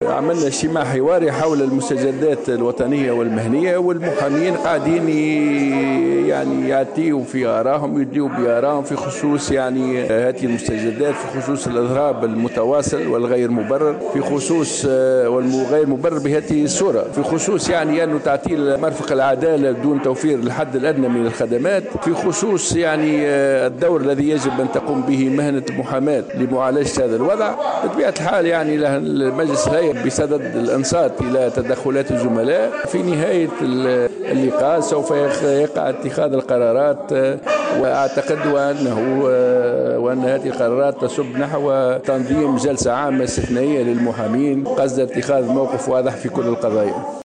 وأضاف بودربالة في تصريح للجوهرة أف- أم على هامش لقاء نظمته الهيئة الوطنية للمحامين، للنظر في آخر المستجدات المهنية والوطنية، أن تعطيل المرفق القضائي طيلة هذه الفترة، دون توفير الحد الأدنى من الخدمات للمواطنين ليس مُبررا.